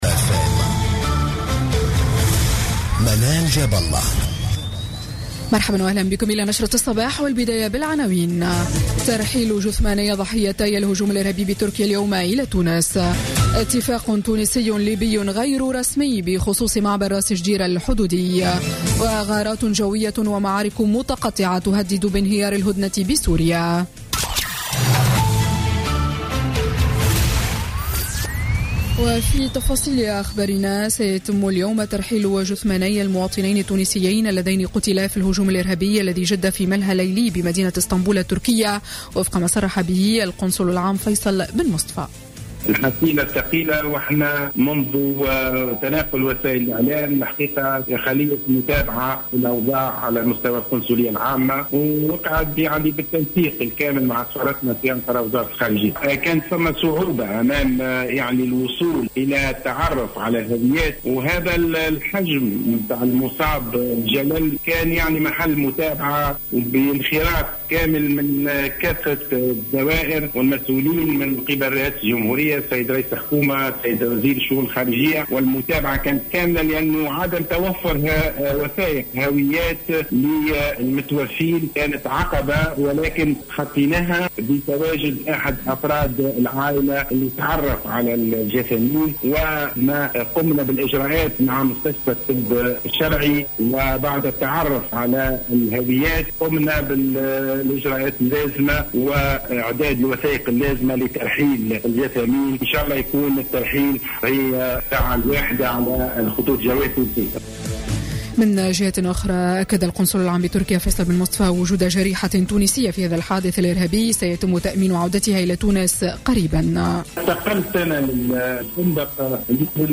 نشرة أخبار السابعة صباحا ليوم الاثنين 2 جانفي 2017